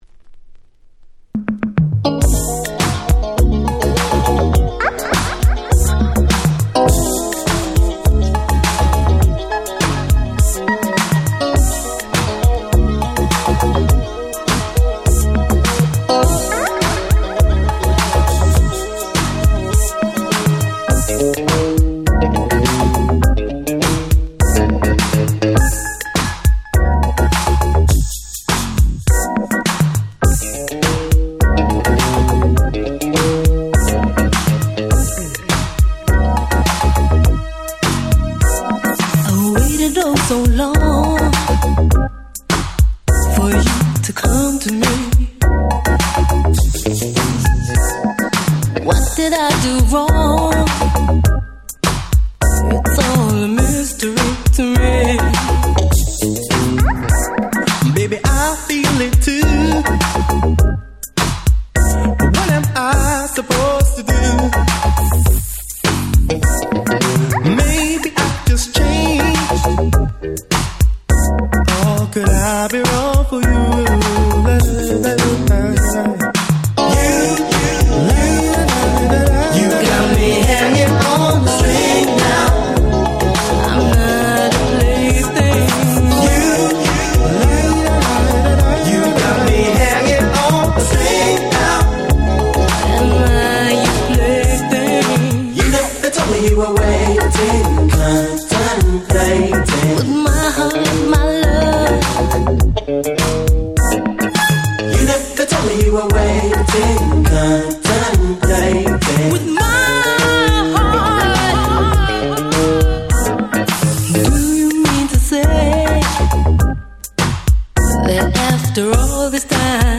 UK Soul Classics !!